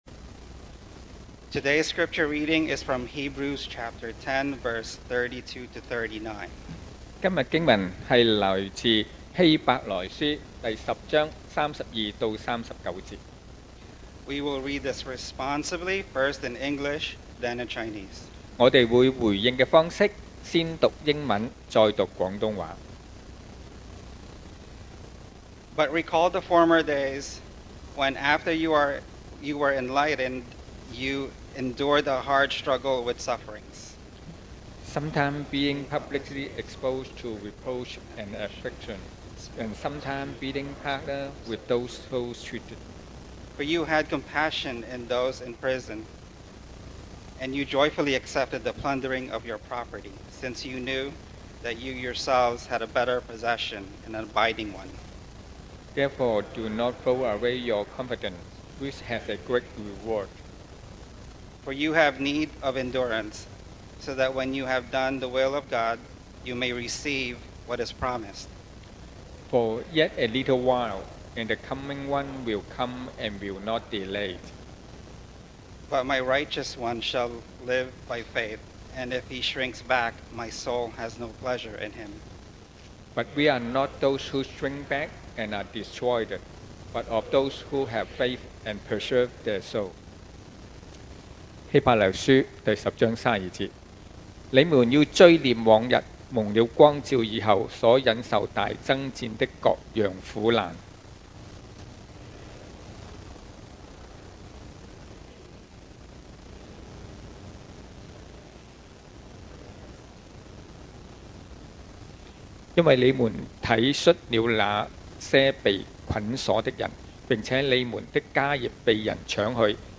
Series: 2024 sermon audios
Service Type: Sunday Morning